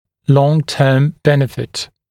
[ˌlɔŋ’tɜːm ‘benɪfɪt][лон-тё:м ‘бэнифит]долгосрочная польза